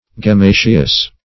Search Result for " gemmaceous" : The Collaborative International Dictionary of English v.0.48: Gemmaceous \Gem*ma"ceous\, a. Of or pertaining to gems or to gemm[ae]; of the nature of, or resembling, gems or gemm[ae].